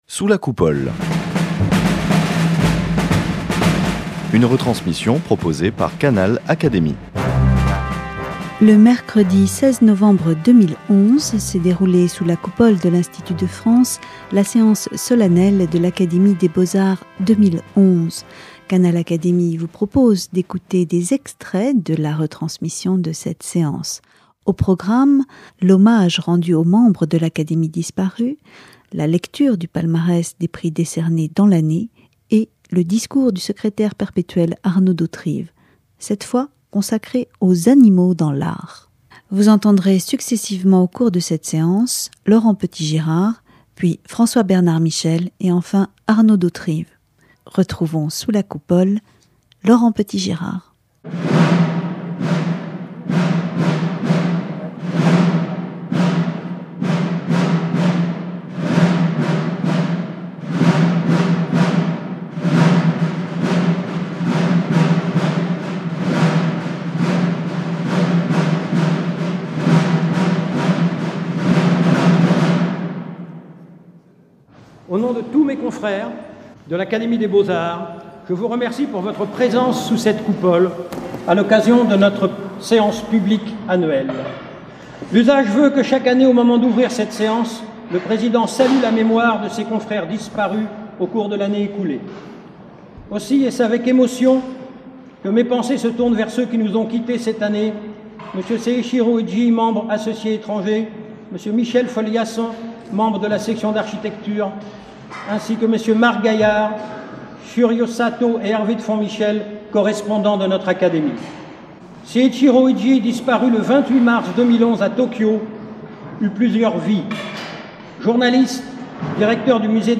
Retransmission de la Séance solennelle de l’Académie des beaux-arts 2011
Le mercredi 16 novembre 2011, s’est déroulée sous la coupole de l’Institut de France, la séance solennelle de rentrée 2011 de l’Académie des beaux-arts.
Le président en exercice cette année, le compositeur et chef-d'orchestre Laurent Petitgirard, a débuté la séance solennelle en rendant hommage aux membres de l'Académie décédés cette année.